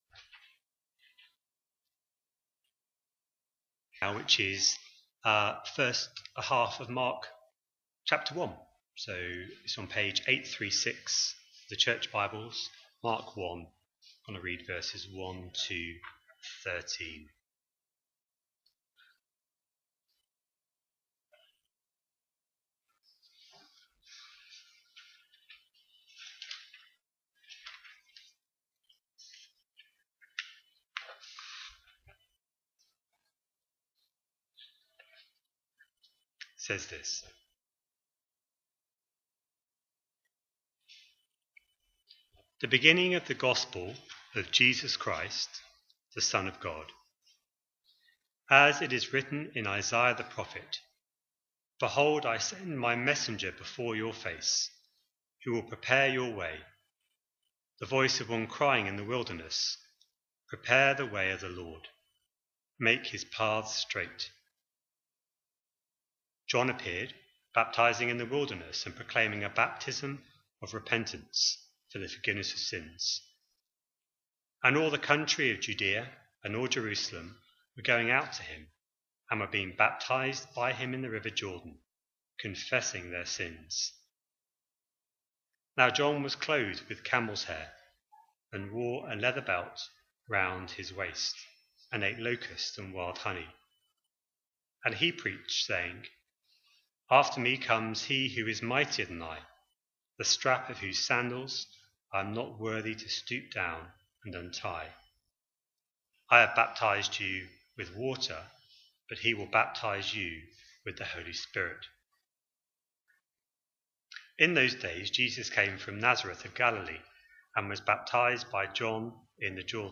A sermon preached on 5th October, 2025, as part of our Mark 25/26 series.